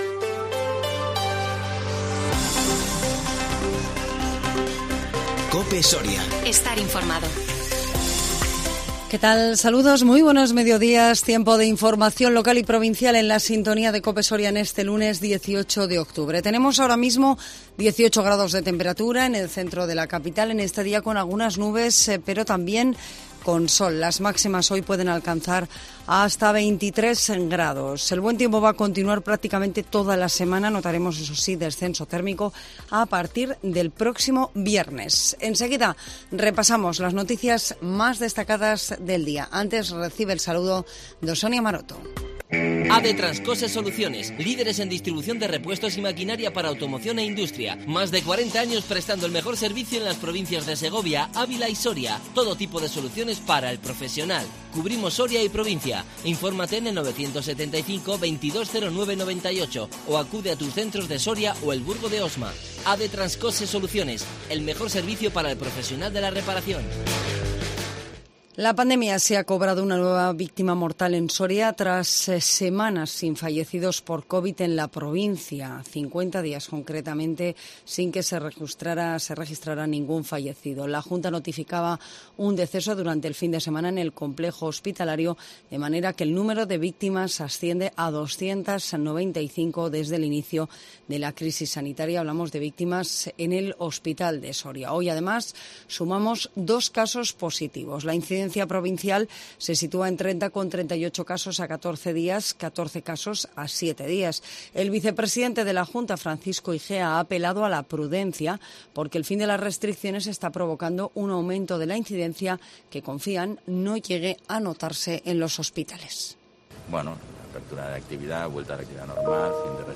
INFORMATIVO MEDIODÍA 18 OCTUBRE 2021